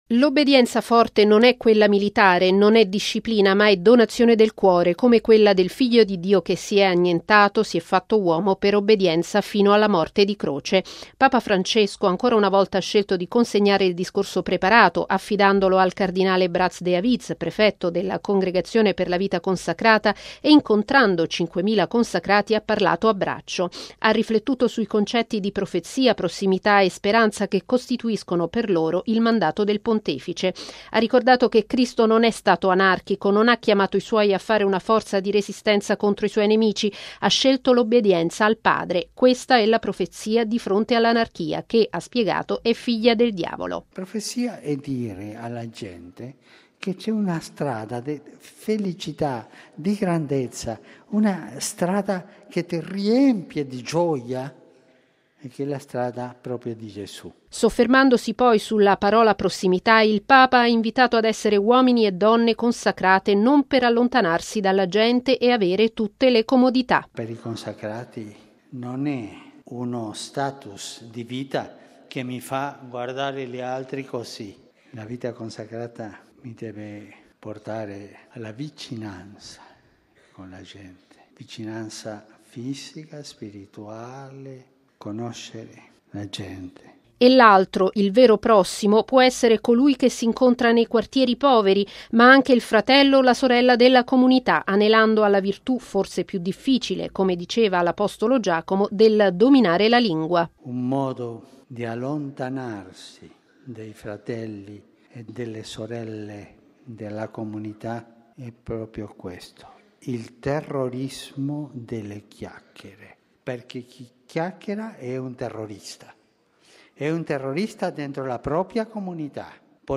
Questa l’esortazione di Papa Francesco nel ricevere in Aula Paolo VI i partecipanti al Giubileo della Vita Consacrata, che ricorre domani: nella festa della Presentazione del Signore, il Pontefice presiederà alle 17.30 la Santa Messa per i consacrati in occasione del loro Giubileo, nella 20.ma Giornata ad essi dedicata, e a chiusura dell’Anno della Vita Consacrata. Il servizio